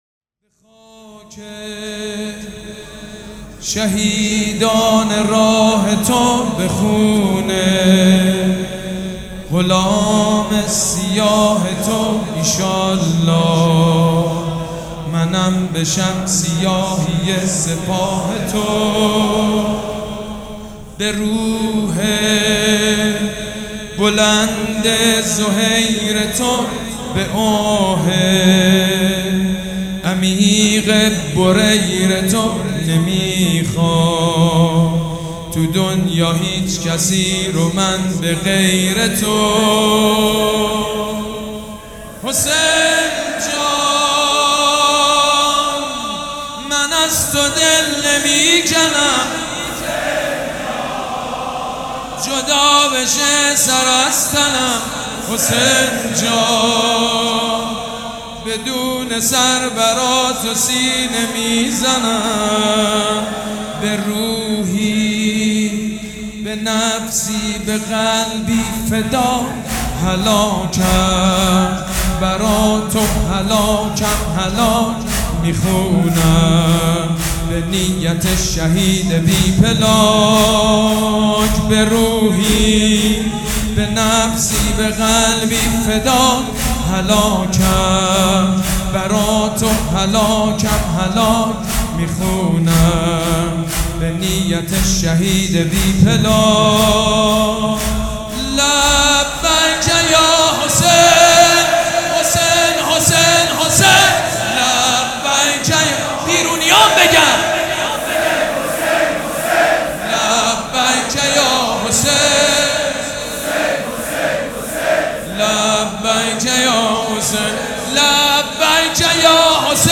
حاج سید مجید بنی فاطمه
مراسم عزاداری شب پنجم